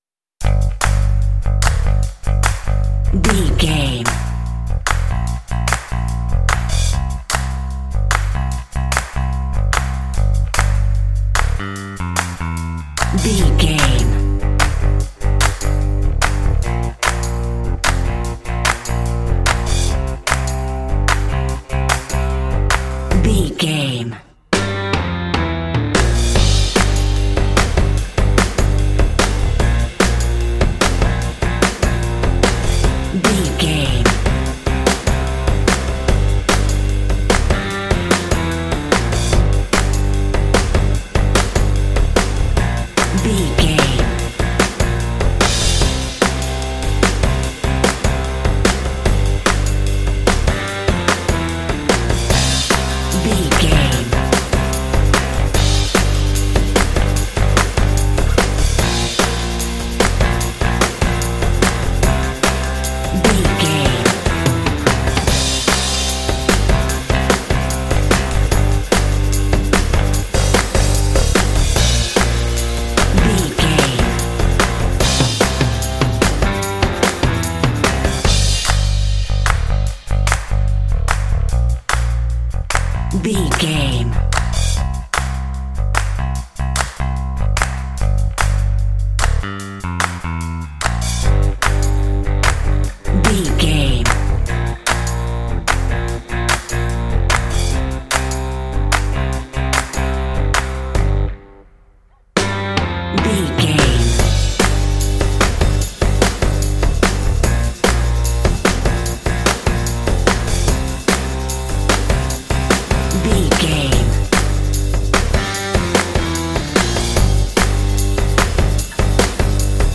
Aeolian/Minor
fun
bright
lively
sweet
brass
horns
electric organ
drums
bass guitar
modern jazz
pop